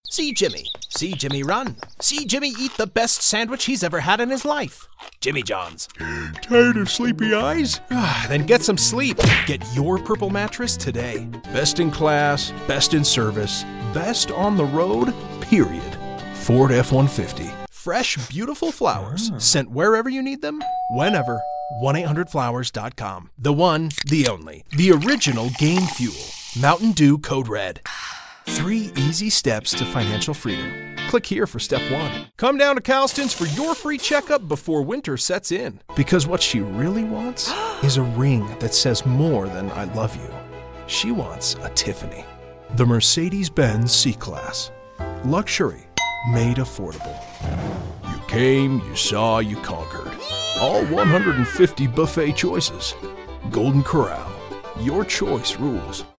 Six-Second Commercial↓ Download
Professionally-built, broadcast quality, double-walled LA Vocal Booth.